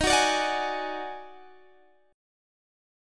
D#mM7bb5 chord